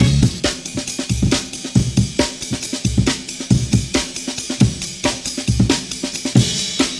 The tempo is 137 BPM.